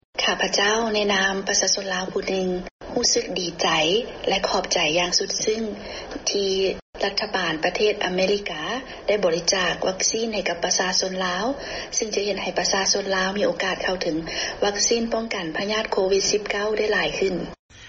ສຽງຂອງຊາວລາວ ທີ່ສະແດງຄວາມດີໃຈ ກ່ຽວກັບຢາວັກຊີນ